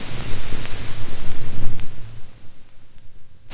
41 NOISE01-L.wav